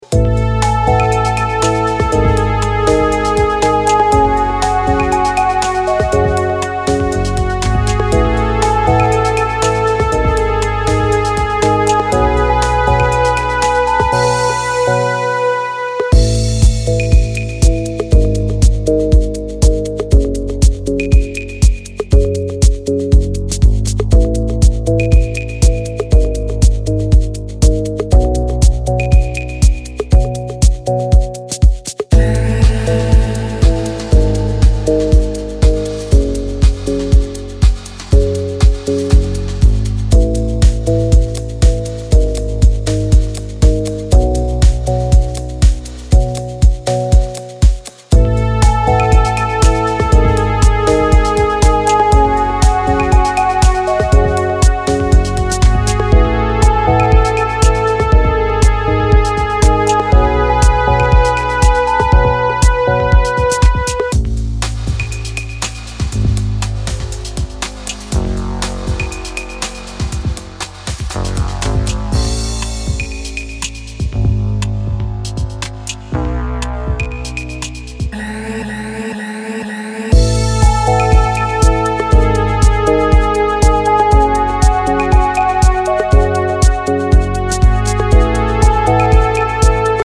as part of a five-track EP of deep house and electro